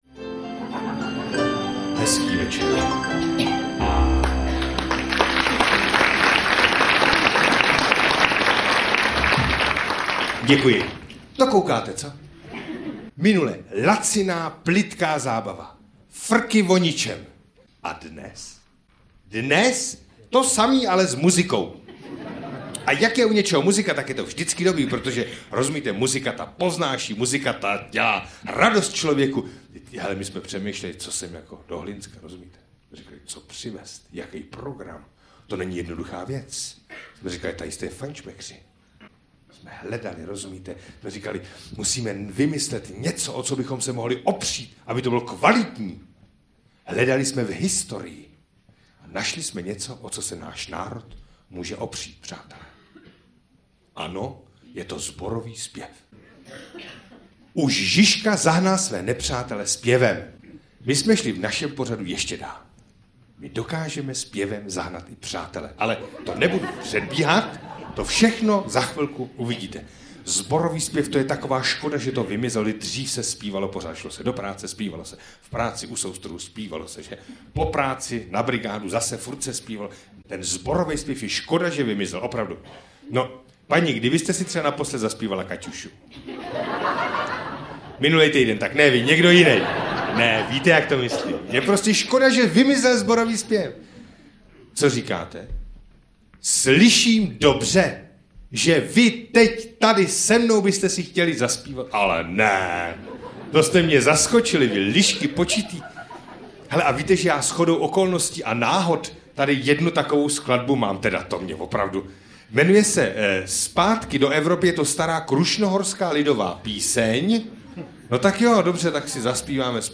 Komické duo Josef Náhlovský a Josef Mladý není třeba představovat. Téměř hodina těch nejvtipnějších scének a dialogů je prostě nedostižná.